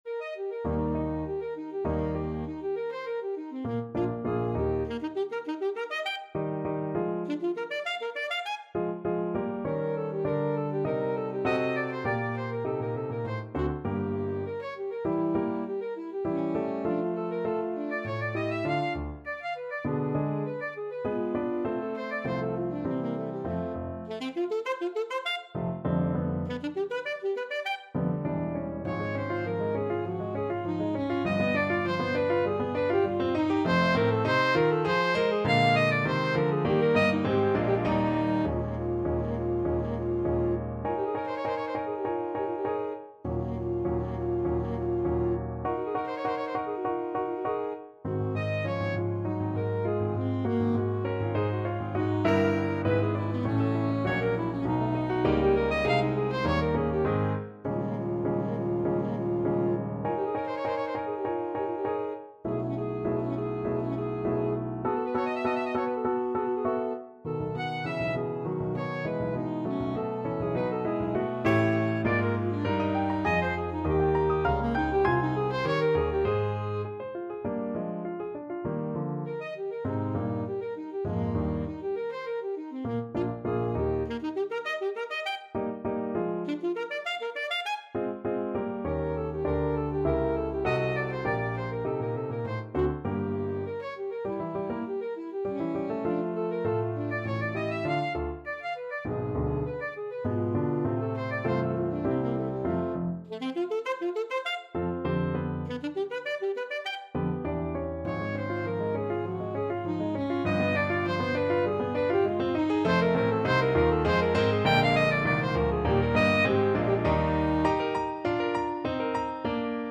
Alto Saxophone
~ = 200 Allegro Animato (View more music marked Allegro)
Classical (View more Classical Saxophone Music)